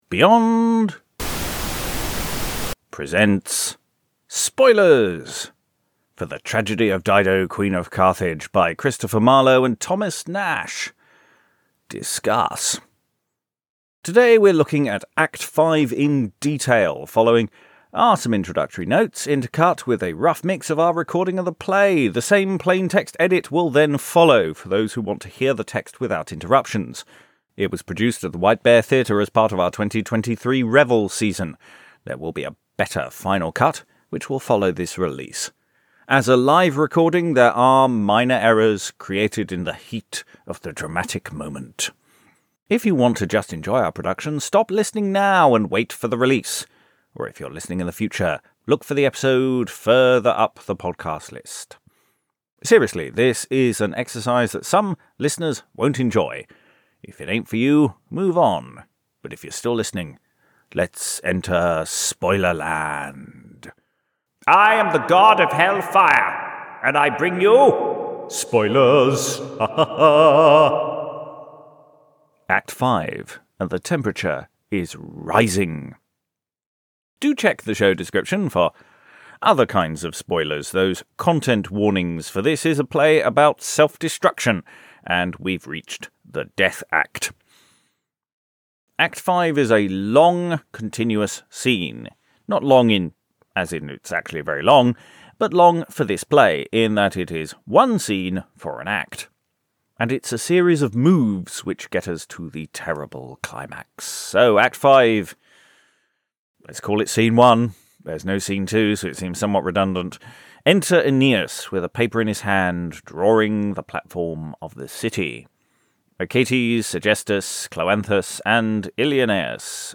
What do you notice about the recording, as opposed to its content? The play was recorded at The White Bear Theatre on Tuesday 12th December 2023. The recording used throughout this episode is a live mix of the performed play – it focuses on the text as writ - though it was a live show and there are some deviations made in the heat of performance.